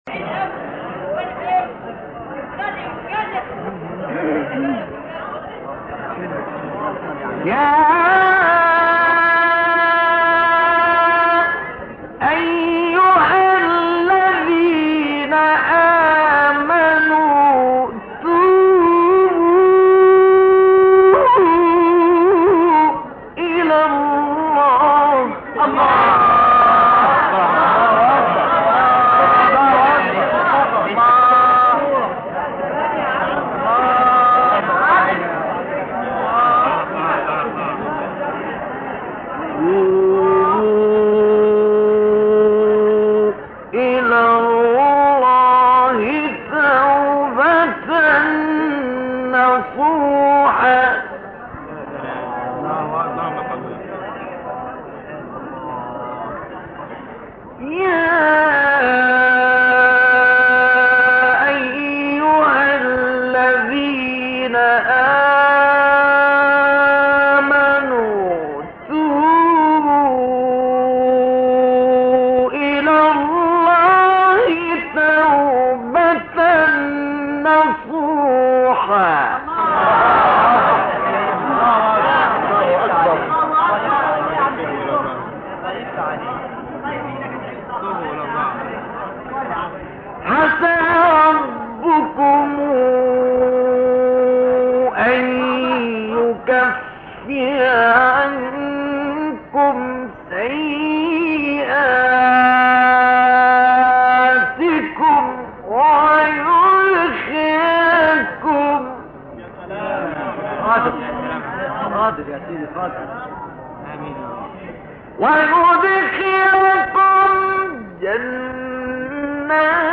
گروه شبکه اجتماعی: تلاوت‌های متفاوت آیه 8 سوره مبارکه تحریم با صوت مصطفی اسماعیل را می‌شنوید.
مقطعی از تلاوت در سال 1960 میت غمر